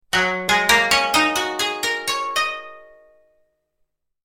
Chinese-musical-phrase.mp3